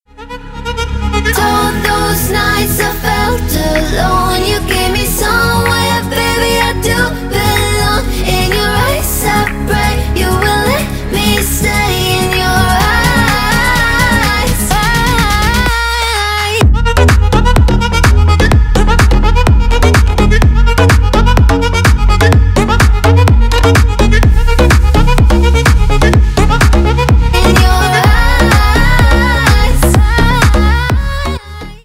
Громкие рингтоны / Клубные рингтоны